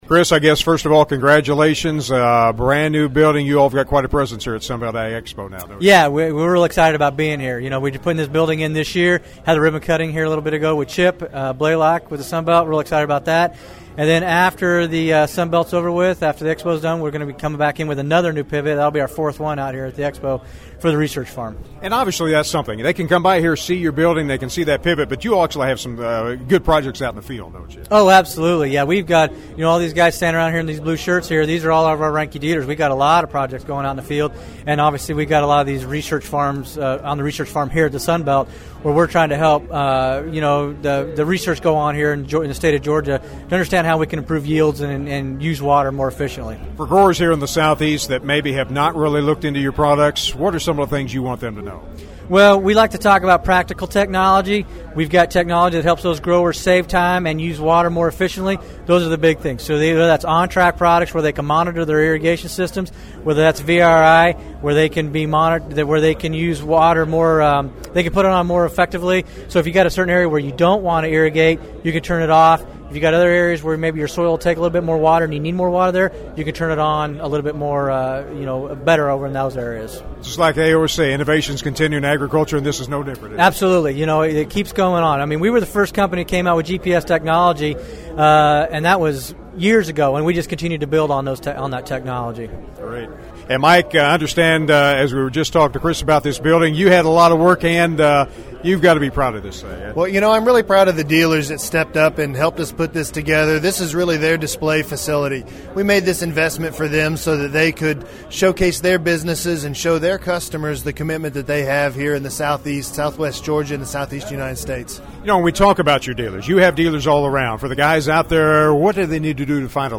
One event held during the annual Sunbelt Ag Expo in Moultrie, Georgia was a ribbon-cutting of the brand new building for Reinke, as the internationally known company is able to even better showcase their irrigation systems and components.